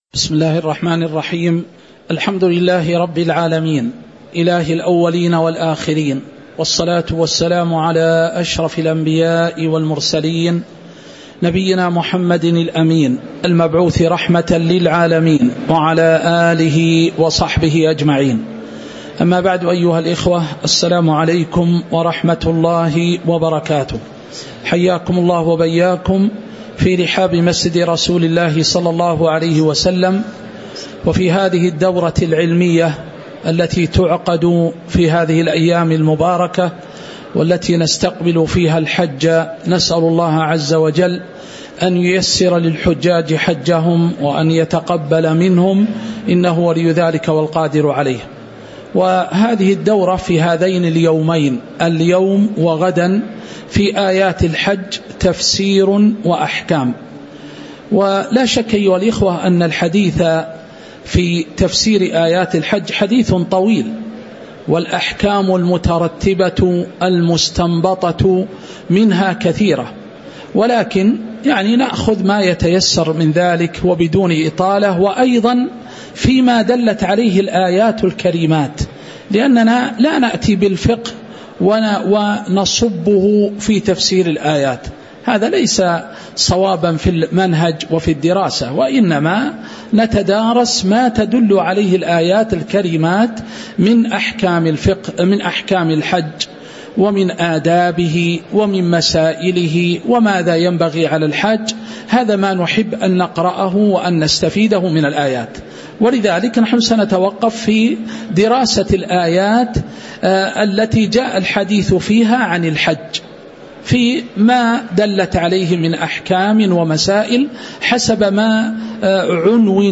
تاريخ النشر ٢٠ ذو القعدة ١٤٤٦ هـ المكان: المسجد النبوي الشيخ